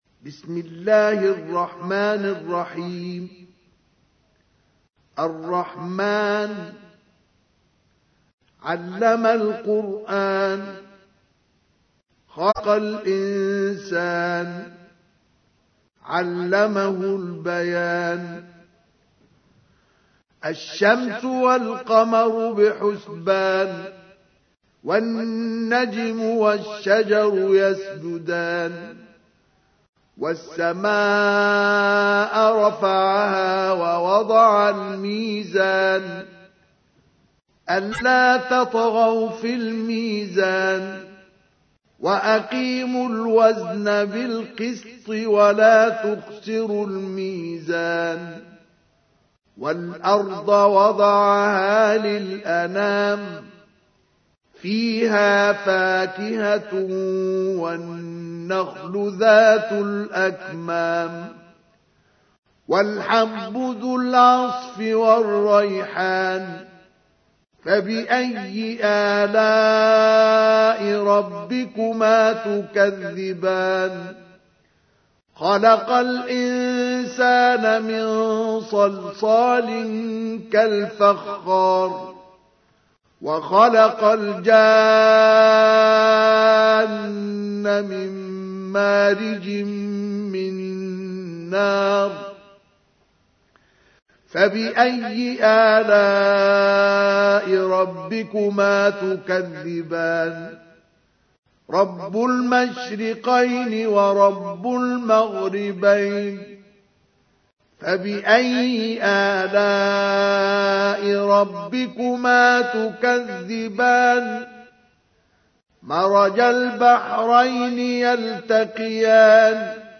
تحميل : 55. سورة الرحمن / القارئ مصطفى اسماعيل / القرآن الكريم / موقع يا حسين